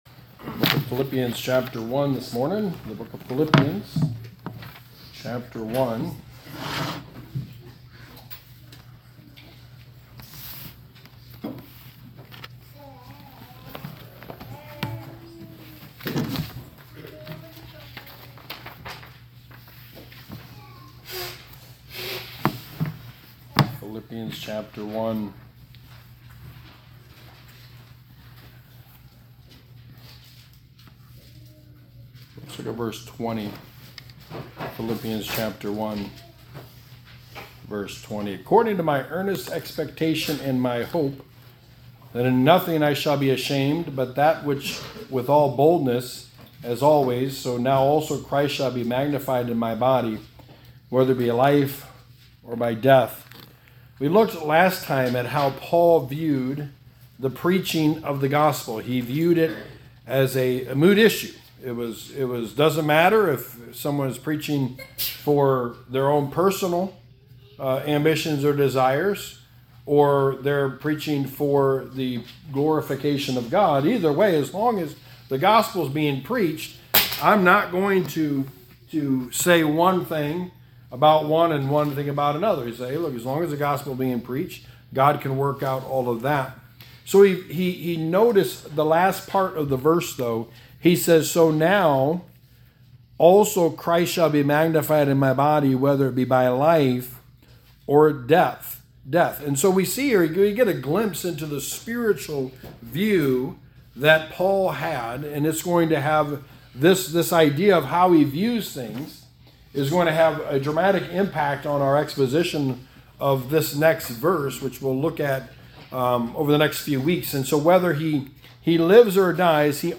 Sermon 7: The Book of Philippians: To Live Is Christ
Passage: Philippians 1:20-21 Service Type: Sunday Morning